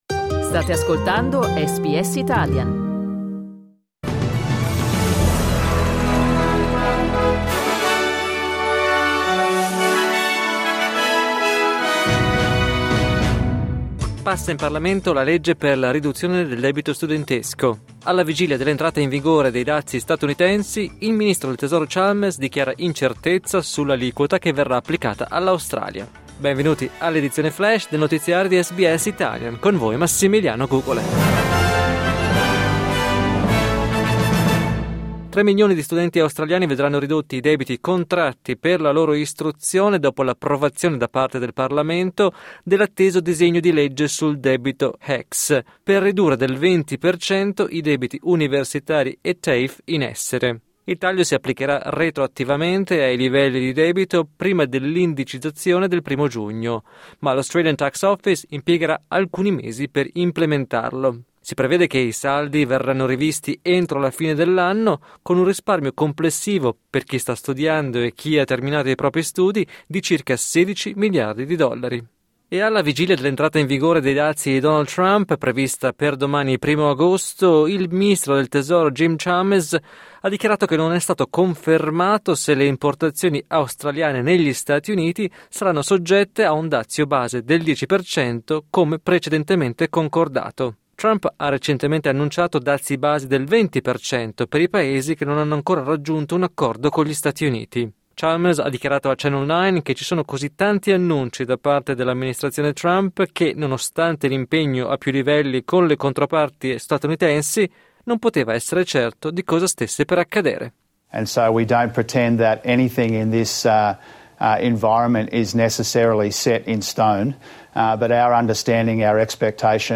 News flash giovedì 31 luglio 2025